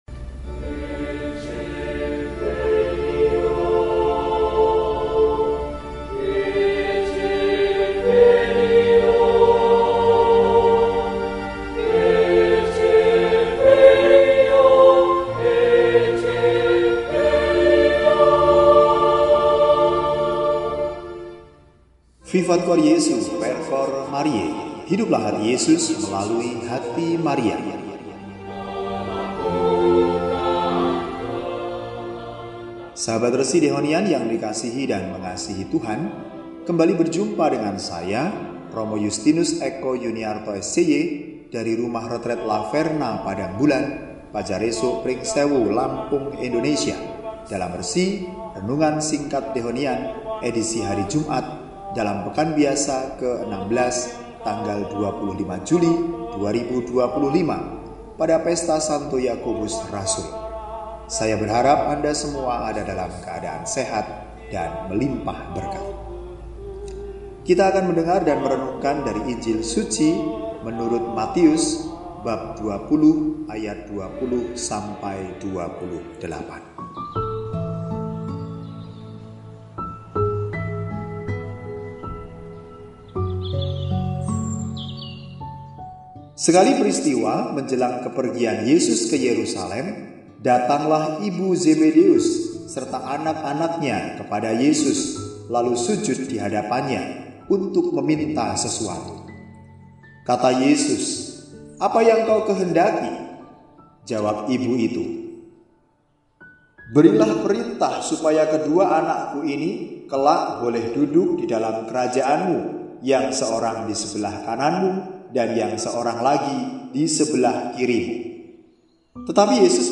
Jumat, 25 Juli 2025 – Pesta Santo Yakobus, Rasul – RESI (Renungan Singkat) DEHONIAN